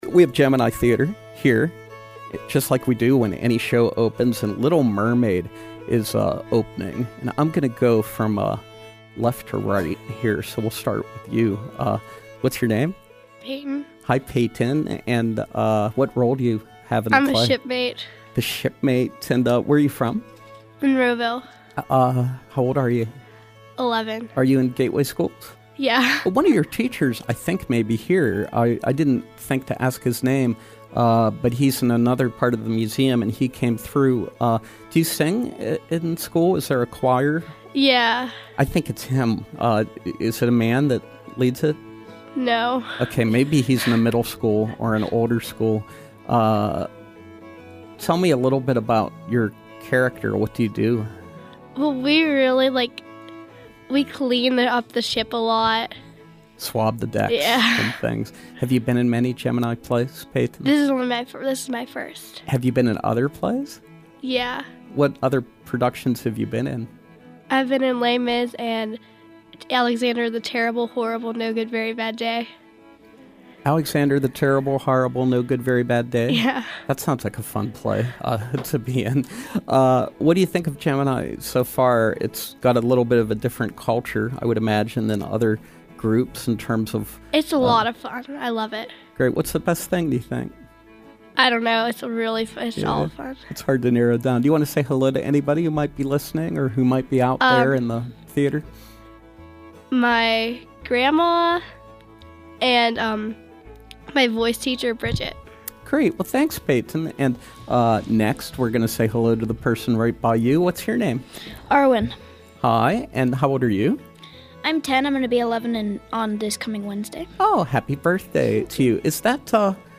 The Gemini Theater produces original, interactive, children’s musicals which focus on artistic, cultural and educational themes. This week we welcome members of the cast of Little Mermaid as they preview their production live in our studios.